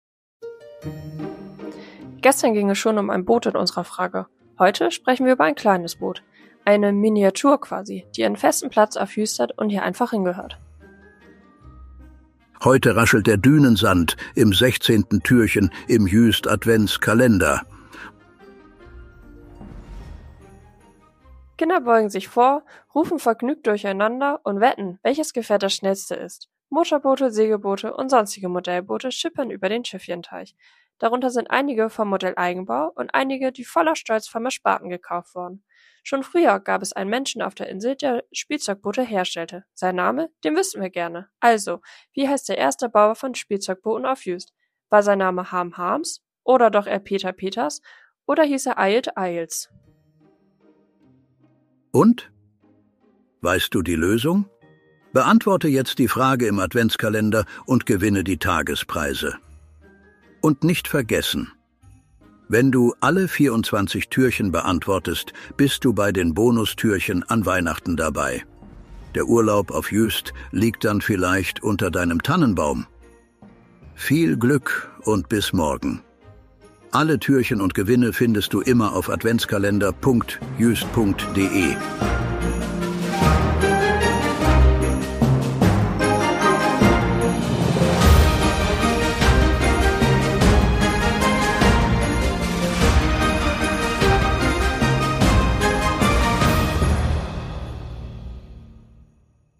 Eingesprochen wird der Adventskalender von vier
guten Geistern der Insel Juist, die sich am Mikro abwechseln und